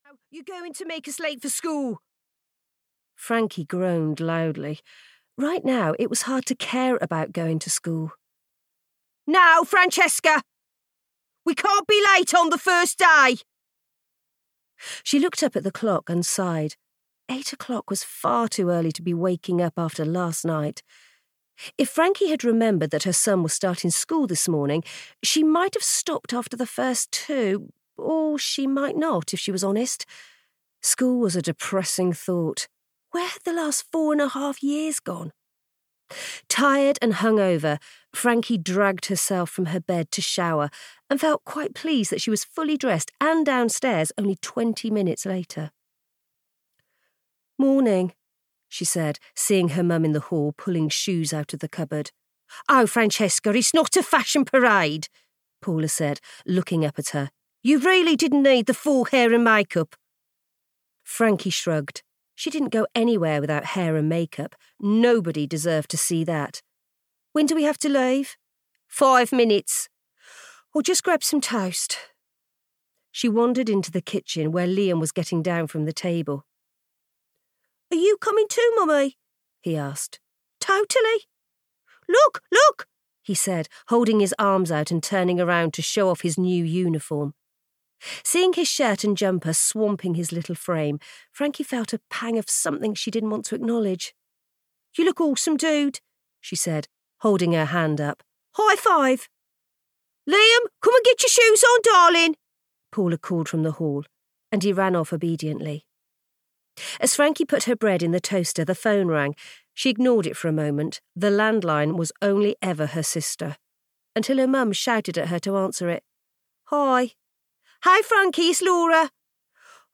I Will Survive (EN) audiokniha
Ukázka z knihy